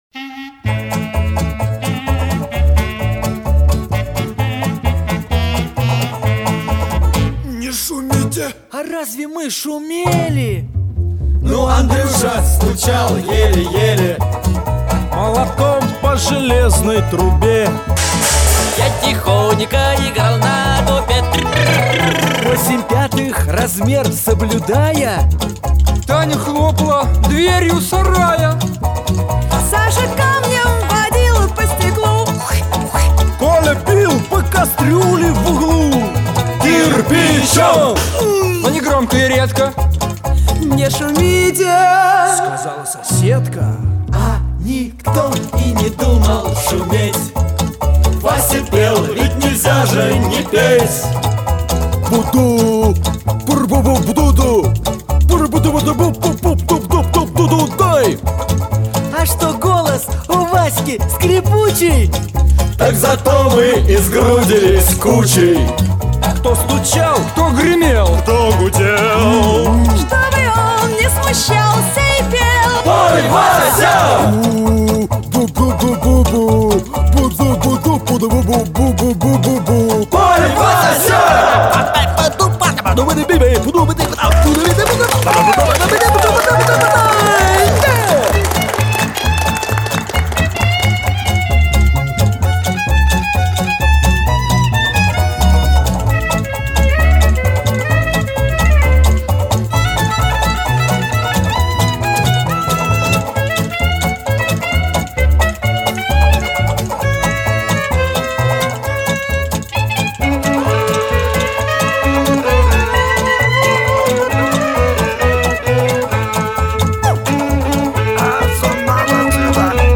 музыка и исполнение